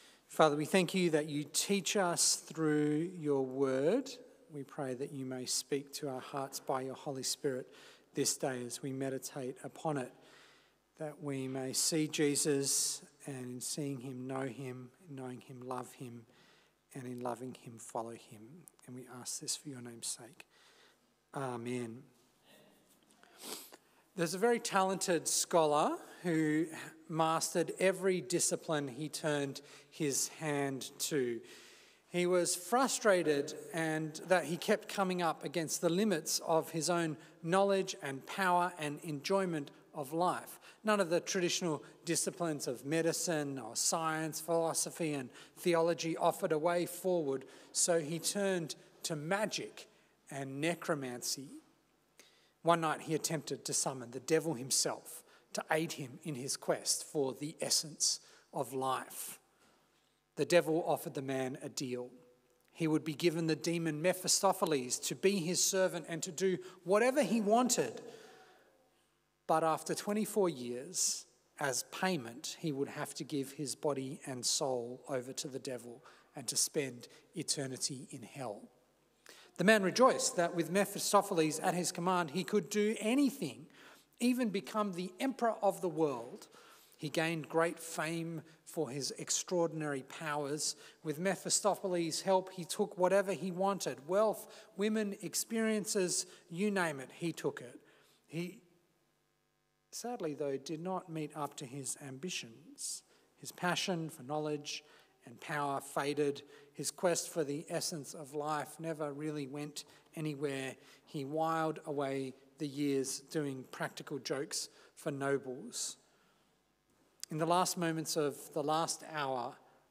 A sermon on Matthew 16:13–17:13